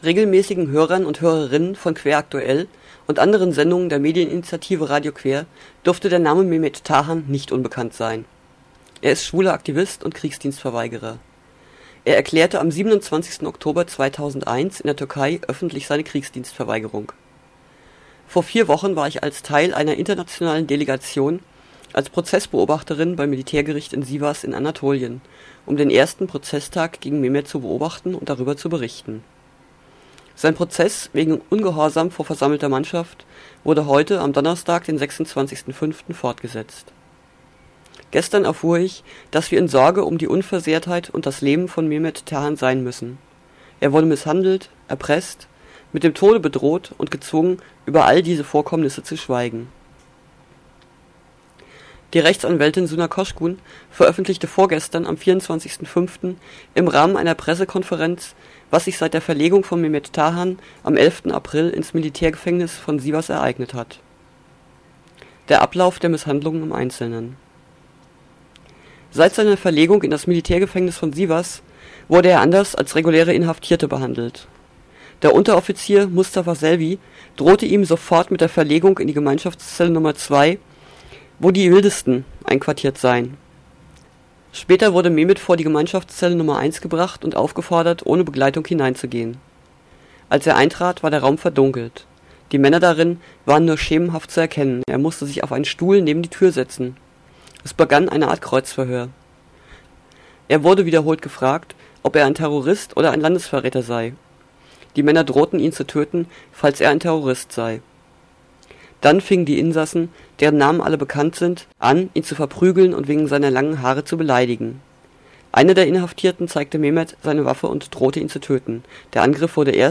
DFG-VK Mainz: Reportage (MP3)
Radiobeitrag über aktuelle Ereignisse vom 26. Mai 2005